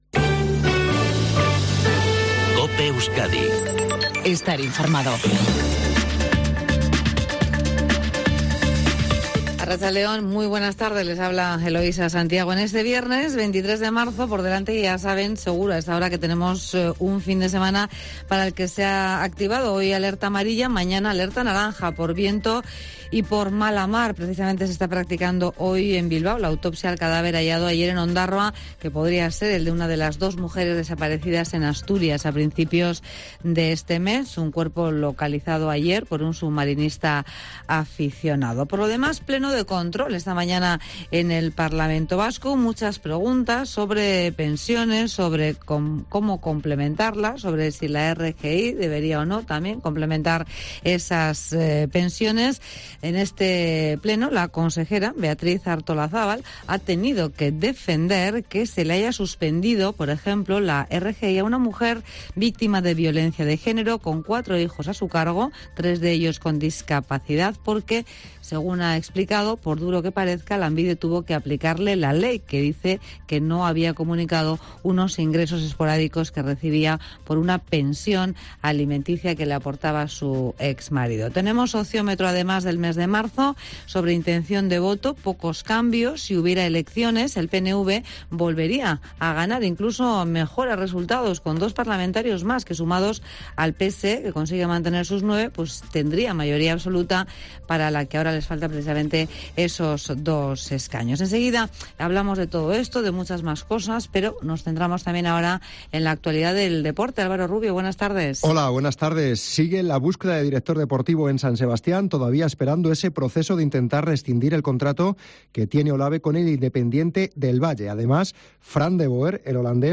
INFORMATIVO MEDIODÍA EUSKADI 14:20